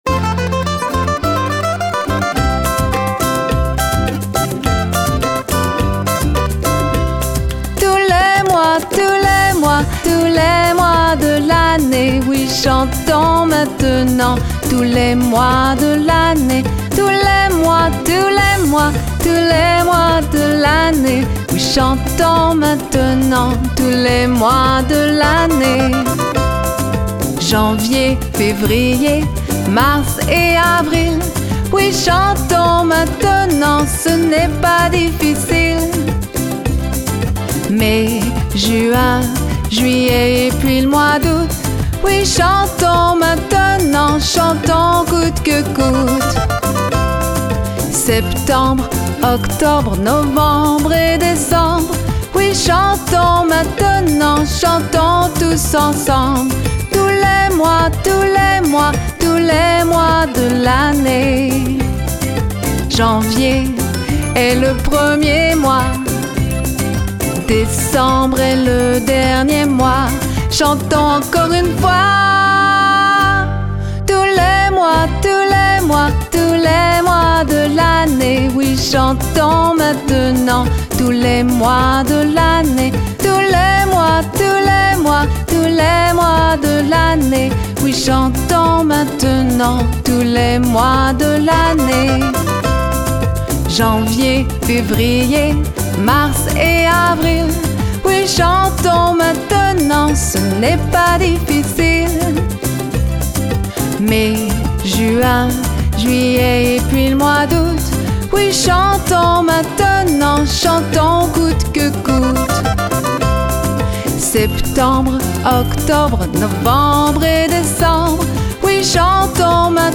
Cette chanson énergique